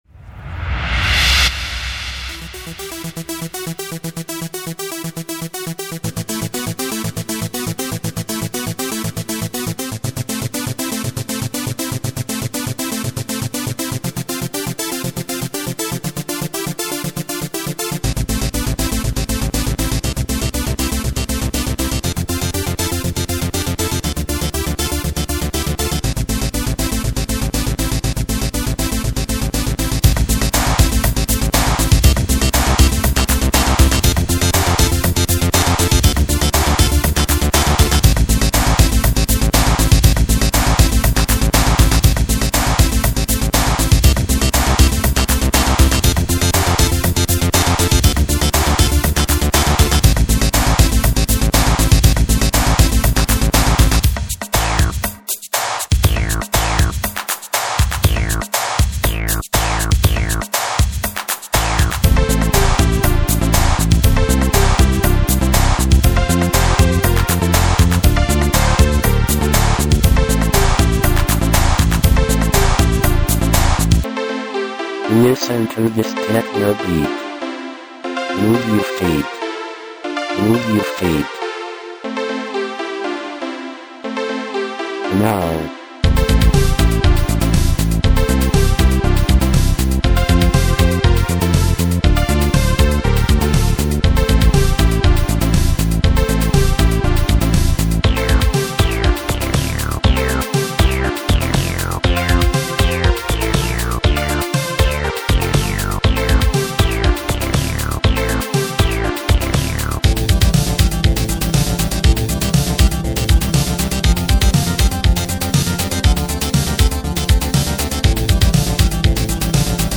80s_techno.mp3